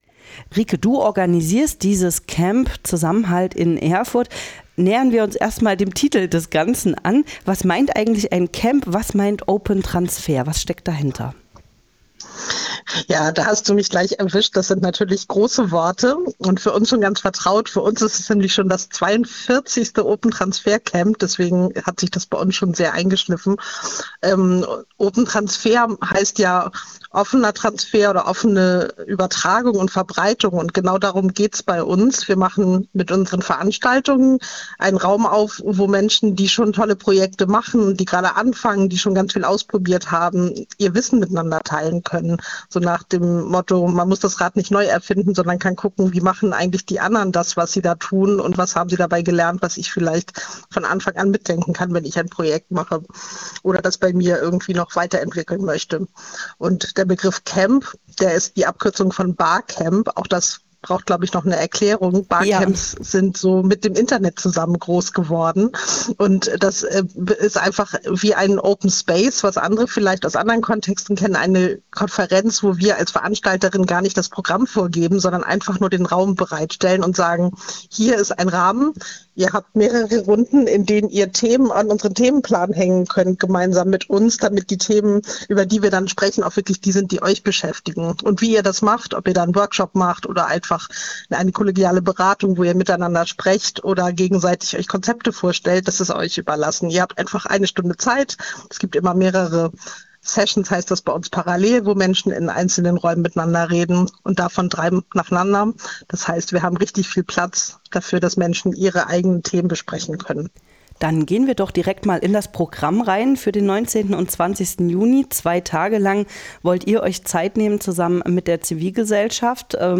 Interview OpenTransferCamp Erfurt 2025_web.mp3